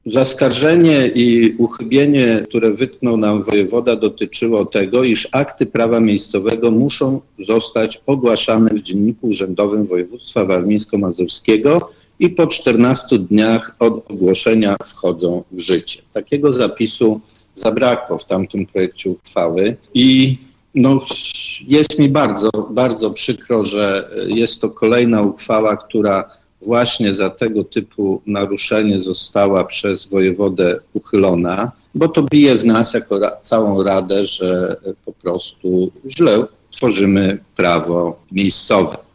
– To nie pierwsza taka sytuacja – mówił Włodzimierz Szelążek – przewodniczący Rady Miejskiej w Ełku.